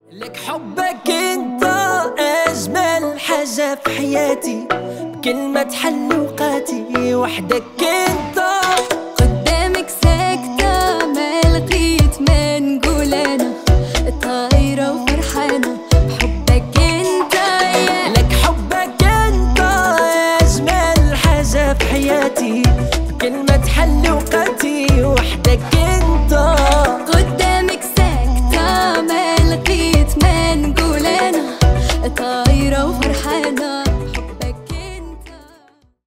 Танцевальные # арабские # восточные